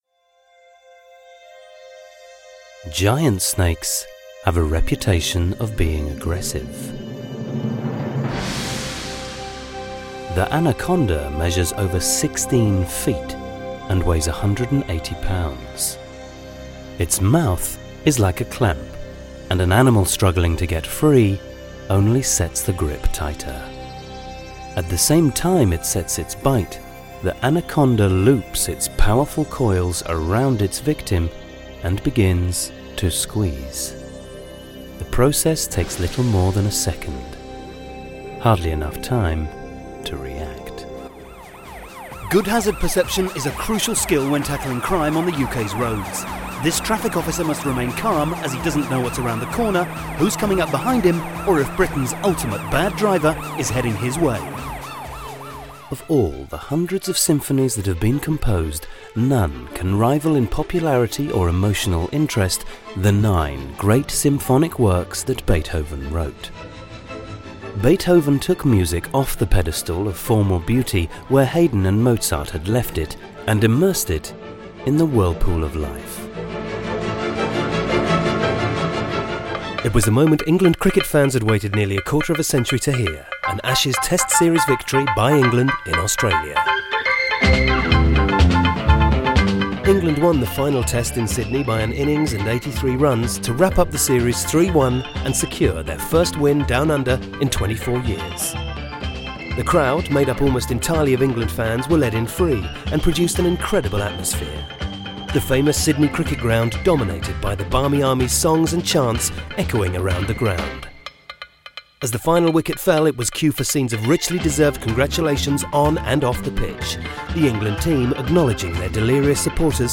Profi-Sprecher Britisch-Englisch.
britisch
Sprechprobe: Industrie (Muttersprache):